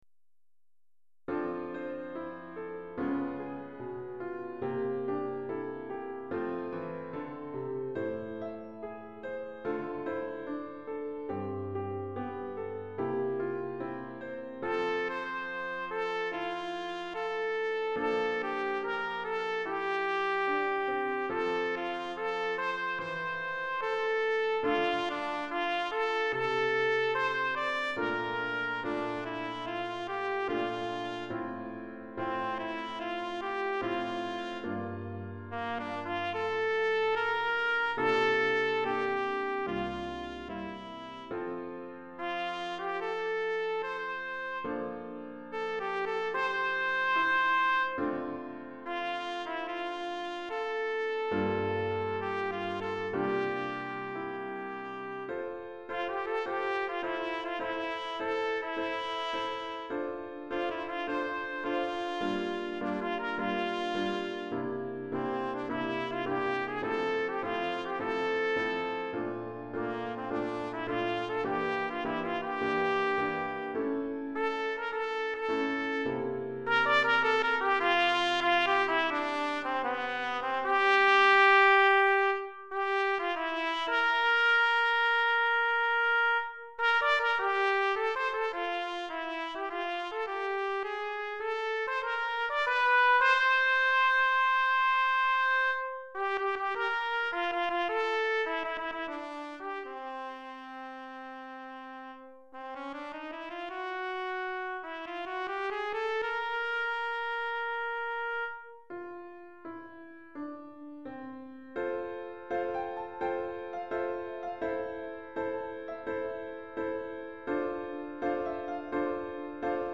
Pour trompette (ou cornet) et piano DEGRE FIN DE CYCLE 1